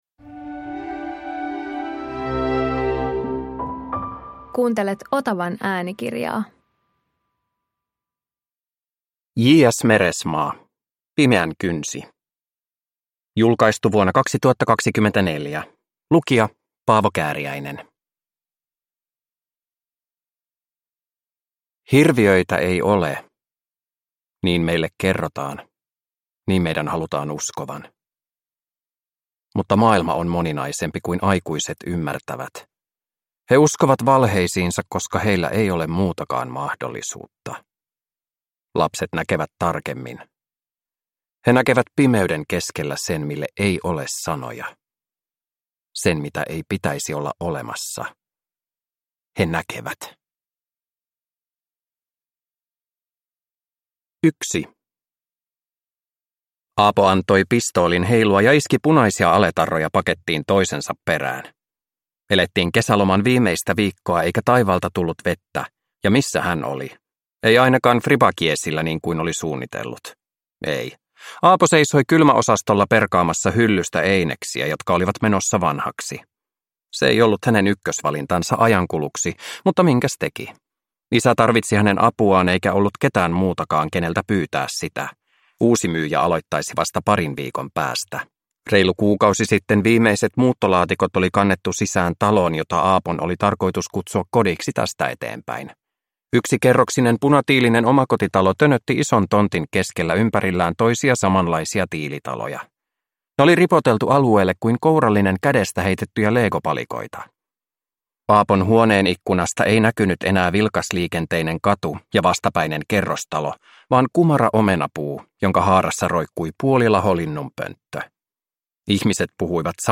Pimeänkynsi – Ljudbok